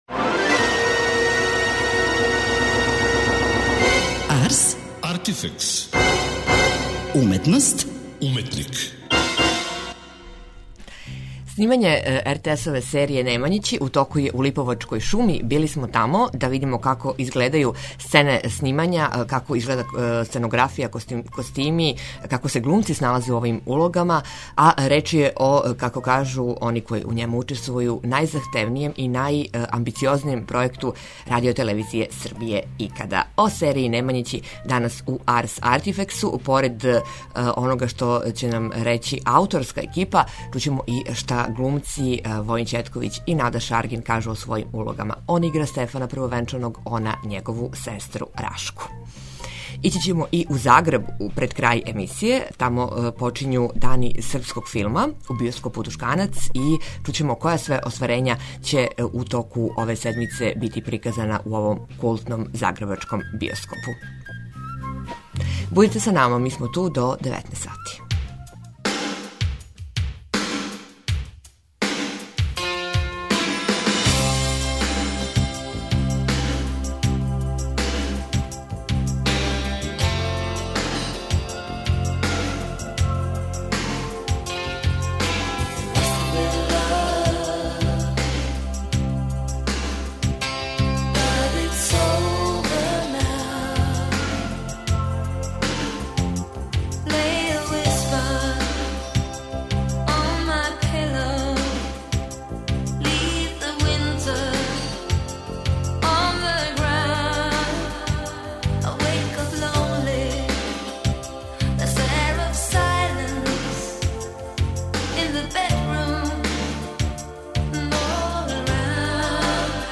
Посетили смо екипу на снимању и доносимо вам репортажу из Липовице.